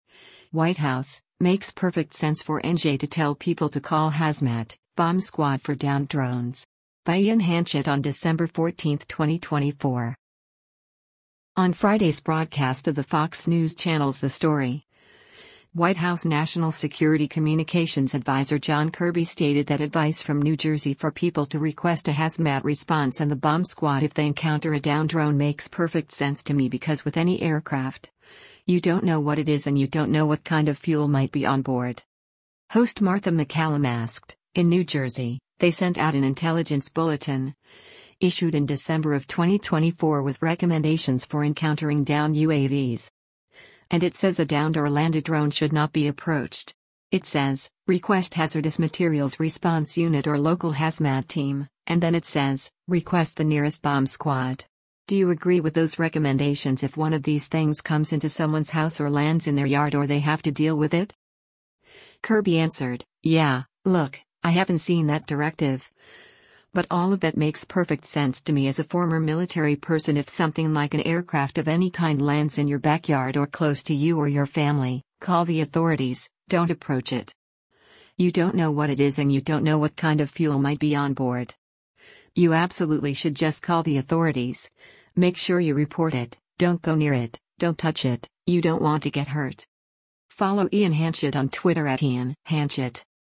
On Friday’s broadcast of the Fox News Channel’s “The Story,” White House National Security Communications Adviser John Kirby stated that advice from New Jersey for people to request a HAZMAT response and the bomb squad if they encounter a downed drone “makes perfect sense to me” because with any aircraft, “You don’t know what it is and you don’t know what kind of fuel might be on board.”